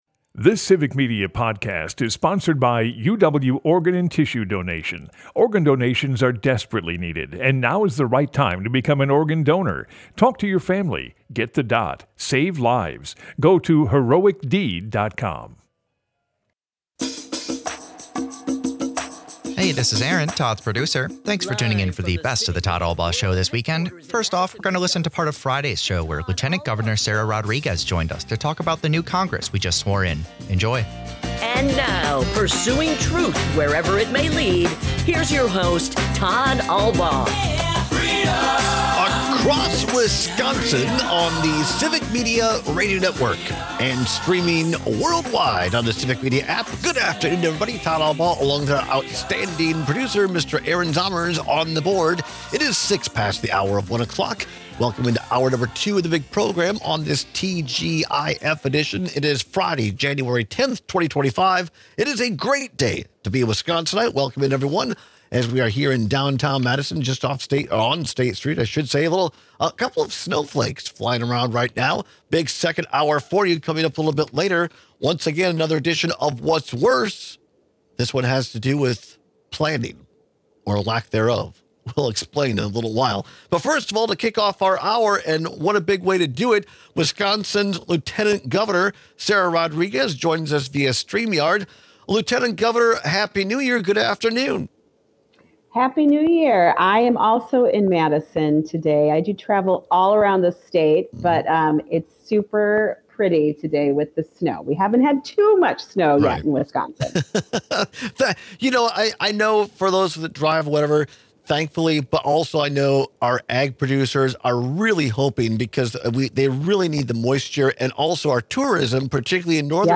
Broadcasts live 12 - 2p across Wisconsin.
We are honored to welcome Lieutenant Governor Sara Rodriguez back to the show! After briefly explaining the latest severe weather declaration from Governor Tony Evers, she explores the makeup of 2025’s new state legislature with us.